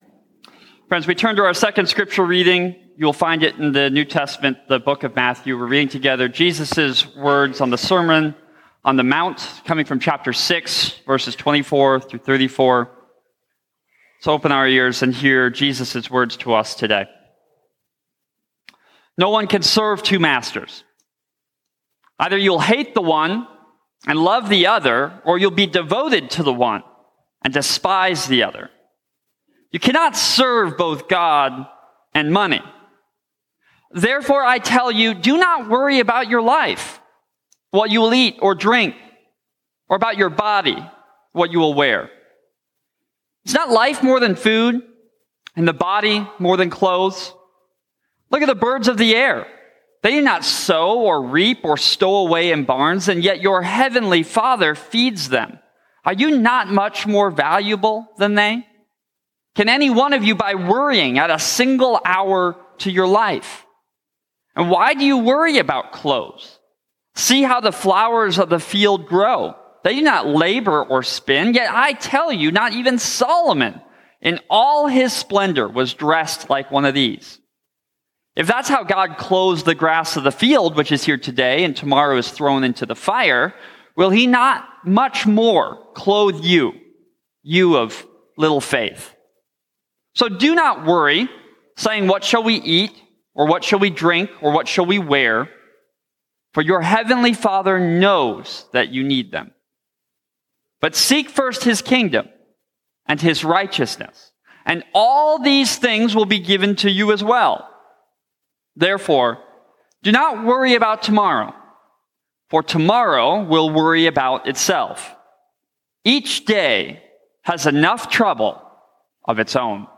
The 8:50 worship service at First Presbyterian Church in Spirit Lake.